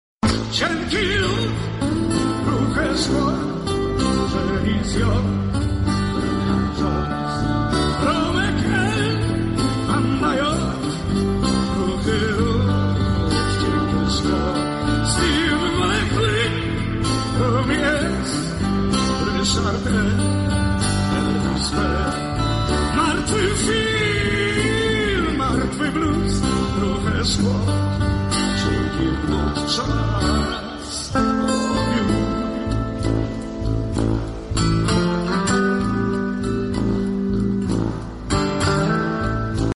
Podczas mszy pogrzebowej wieloletniego lidera zespołu pożegnali także koledzy z Budki Suflera. Wraz z Felicjanem Andrzejczakiem wykonali oni utwór „Czas ołowiu” który od lat osiemdziesiątych był muzycznym wspomnieniem zespołu o wielkich postaciach sceny muzycznej.